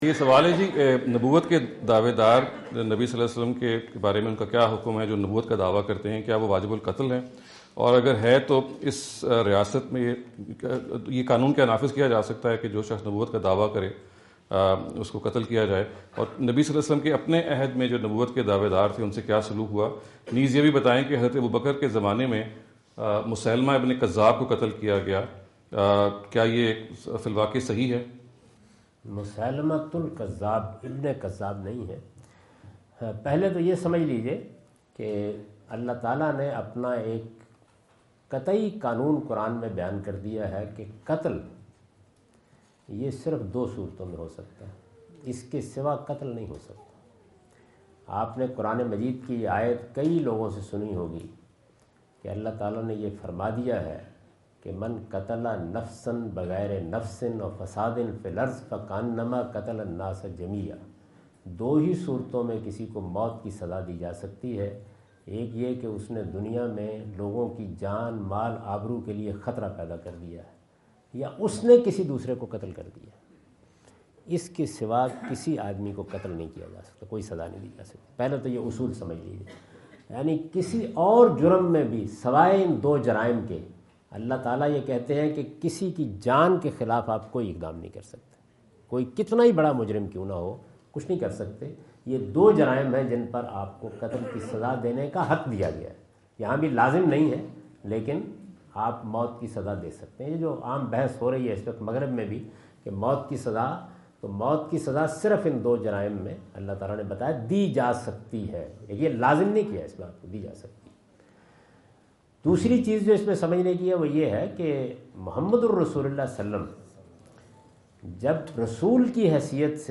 Category: English Subtitled / Questions_Answers /
Javed Ahmad Ghamidi answer the question about "Punishment of Claiming Prophethood" asked at Corona (Los Angeles) on October 22,2017.
جاوید احمد غامدی اپنے دورہ امریکہ 2017 کے دوران کورونا (لاس اینجلس) میں "نبوت کا دعویٰ کرنے کی سزا" سے متعلق ایک سوال کا جواب دے رہے ہیں۔